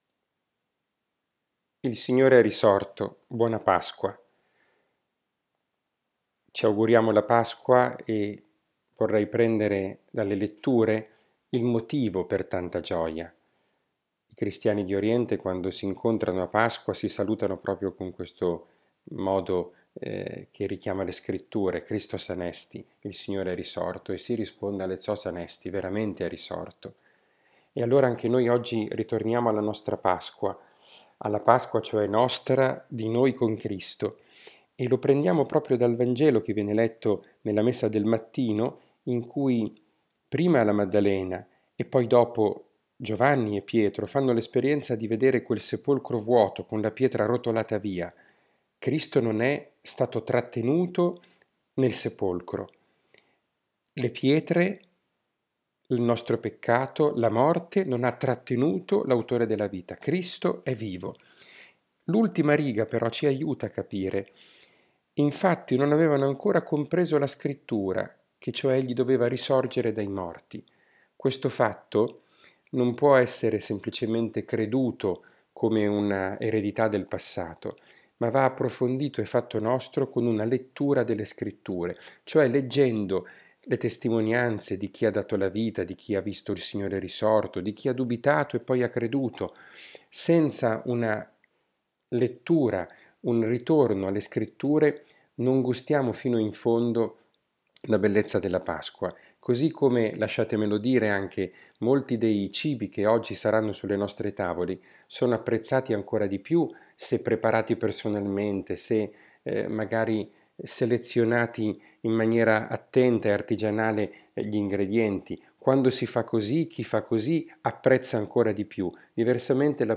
Lo spunto dalla Parola oggi si fa poesia e canto, con le parole della bella e antica sequenza che in questo giorno si legge prima del Vangelo, la lode alla Vittima pasquale che è Cristo vincitore e risorto, commentate in modo poetico da un Santo…
12 Aprile 2020 catechesi, Domenica delle Palme, Parola di Dio, podcast